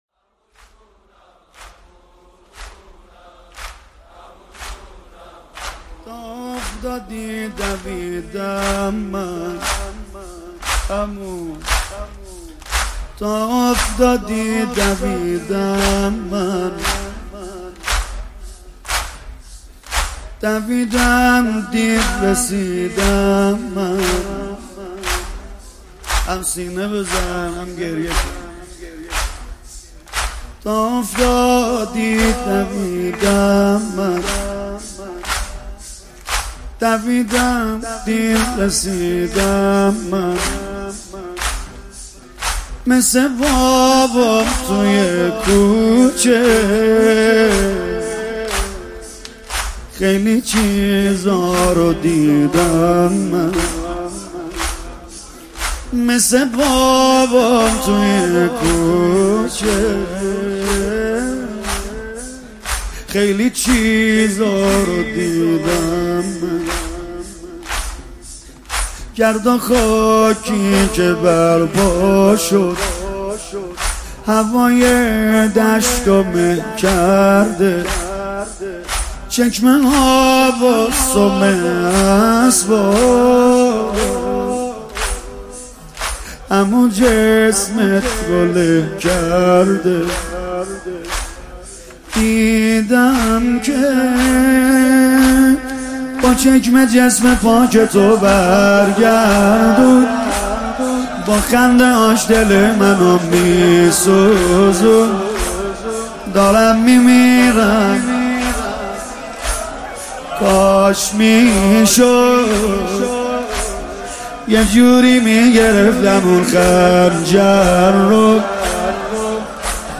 مداحی جدید کربلایی سید رضا نریمانی شب چهارم محرم97 اصفهان،خیابان کمال اسماعیل ،خیابان پاسدارن،حسینیه شهدای بسیج